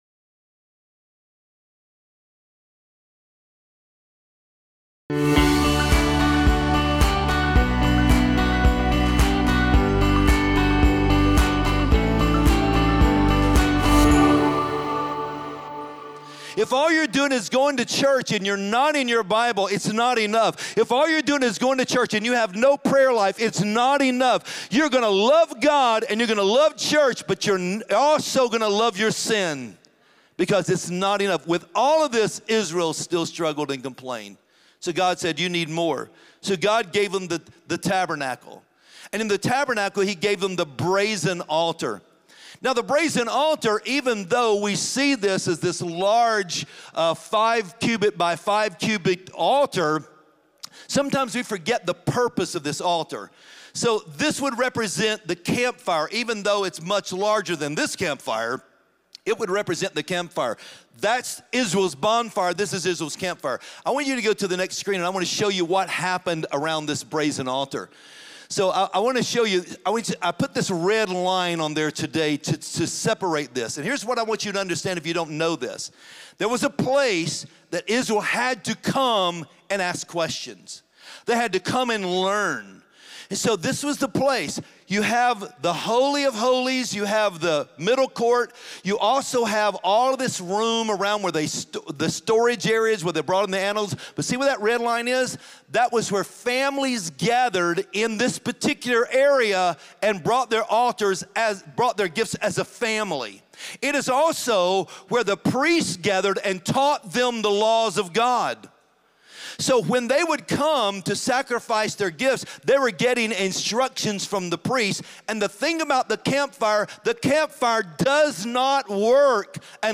Join us this week for the sermon “3 Fires of the Church.”